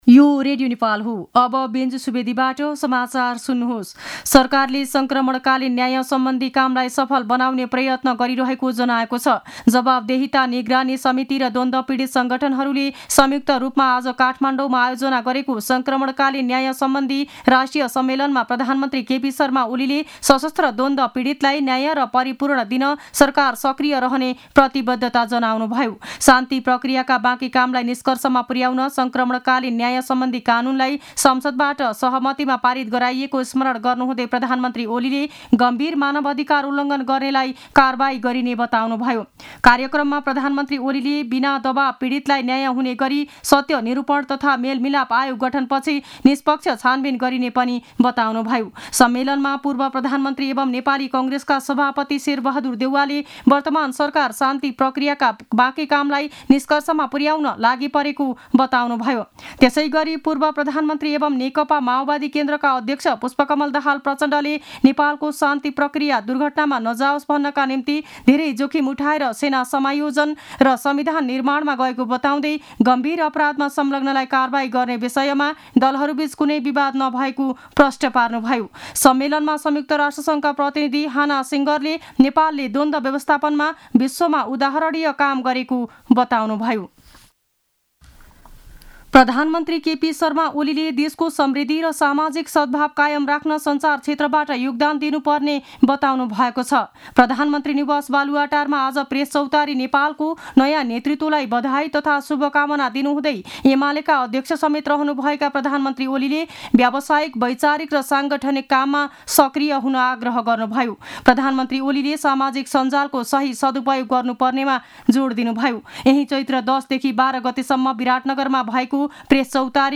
दिउँसो १ बजेको नेपाली समाचार : १४ चैत , २०८१
1-pm-news-1-10.mp3